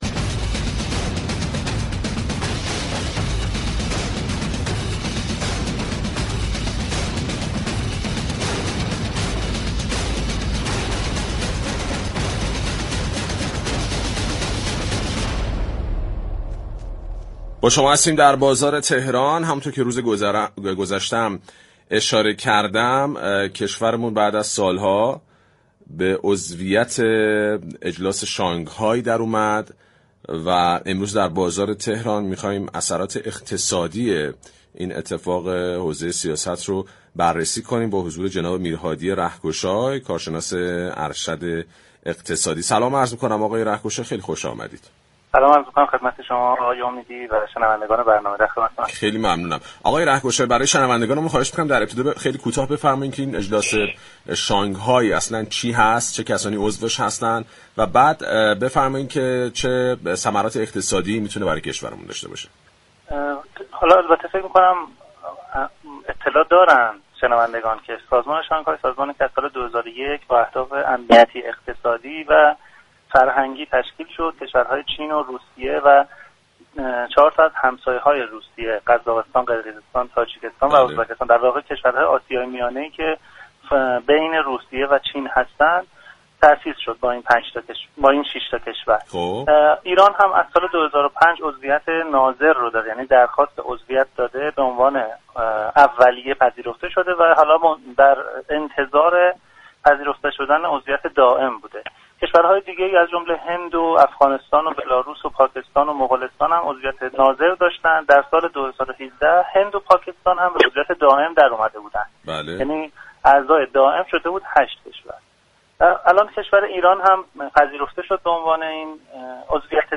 این كارشناس اقتصادی در ادامه گفت: ما در دولت‌های نهم تا دوازدهم تلاش‌های زیادی برای تبدیل عضویت ناظر به عضویت دائم انجام داده بودیم ولی به دلایلی كه بیشتر آن ضعف در دیپلماسی بود، این امر محقق نشد.